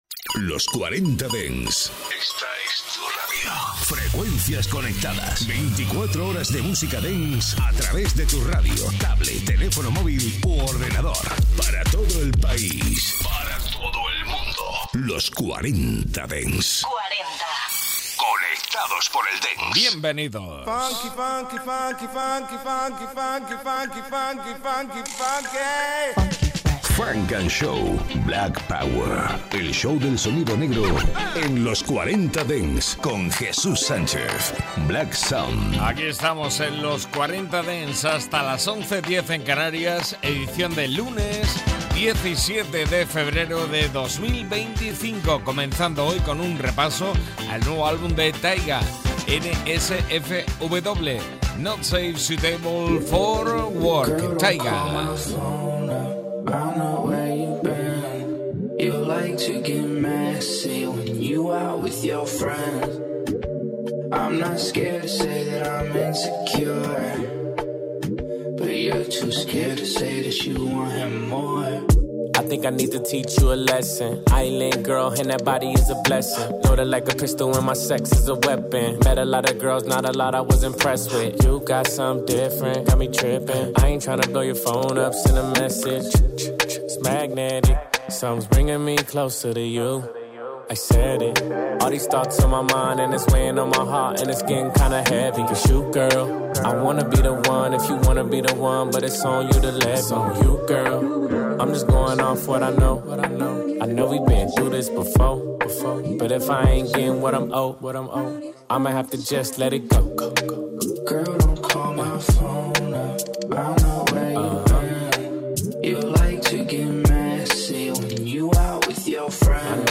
Escucha todos los temazos clásicos y míticos de la música dance de las últimas décadas